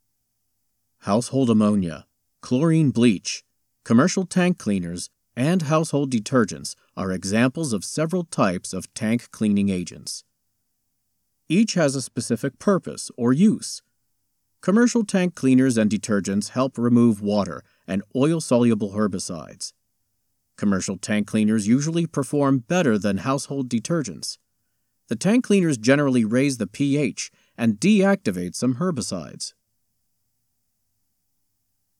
Educational
Tank Cleaners - Educational.mp3